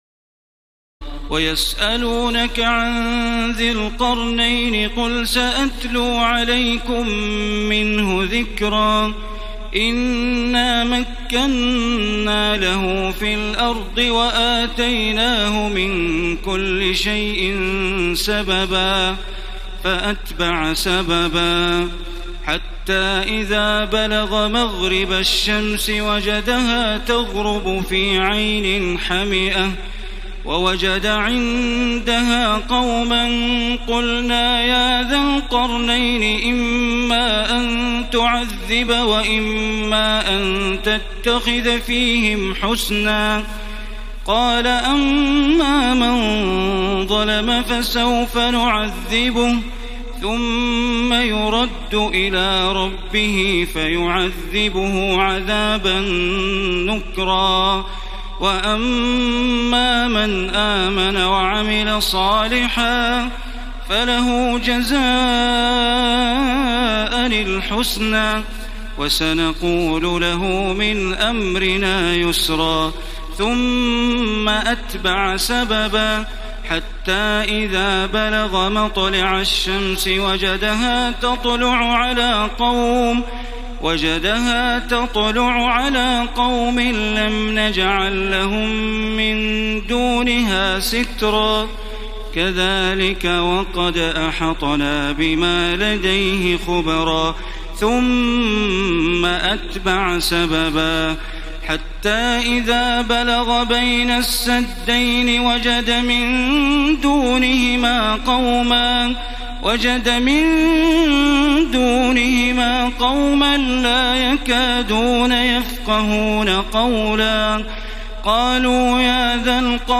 تراويح الليلة الخامسة عشر رمضان 1434هـ من سورتي الكهف (83-110) و مريم كاملة Taraweeh 15 st night Ramadan 1434H from Surah Al-Kahf and Maryam > تراويح الحرم المكي عام 1434 🕋 > التراويح - تلاوات الحرمين